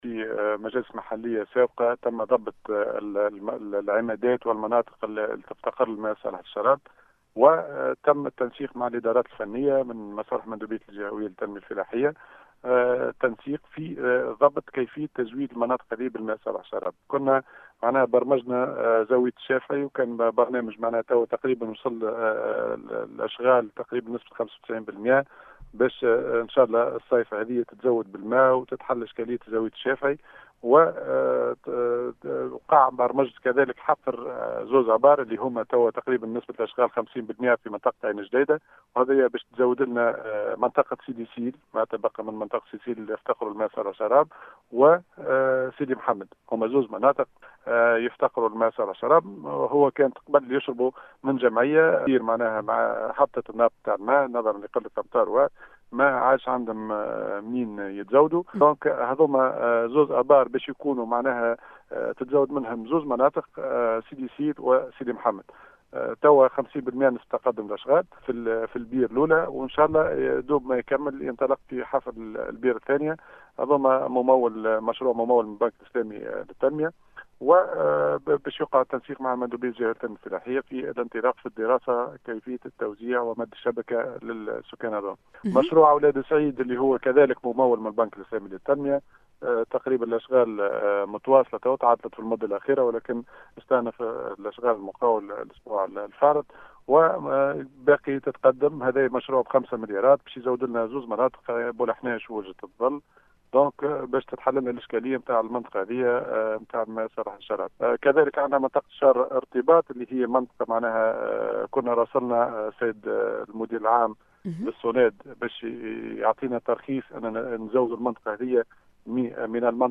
أكّد معتمد تالة جوهر الشّعباني أثناء تدخله اليوم الأحد 7 مارس 2021 ببرنامج بو نجور ويكاند بإذاعة السيليوم أف أم ، أنّه في مجالس محليّة سابقة عقدت لضبط العمادات و المناطق التي تفتقر إلى الماء الصالح للشّراب ، تمّ برمجة زاوية الشّافعي التي وصلت بها الأشغال إلى 95 بالمائة ، حيث سيتم هذه الصائفة تزويدها بالماء .